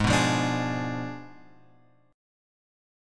G# Diminished 7th